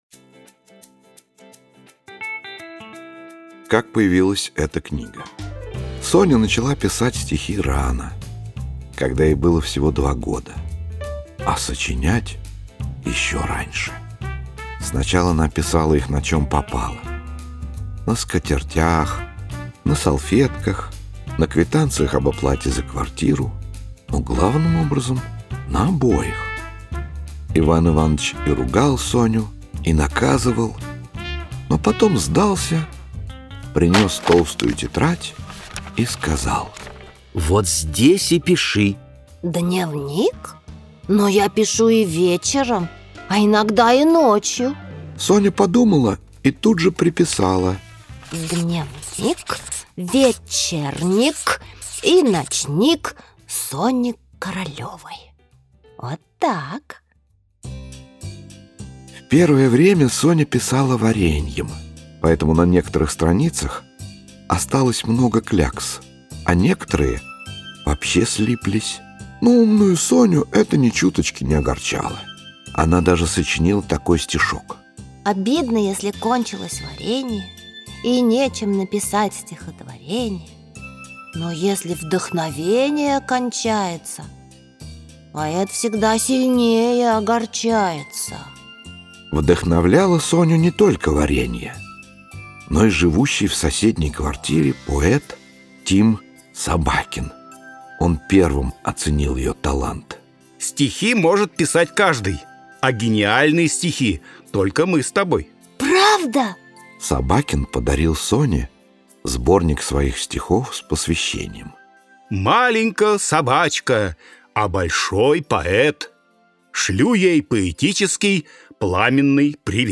Аудиокнига Дневник умной собачки Сони | Библиотека аудиокниг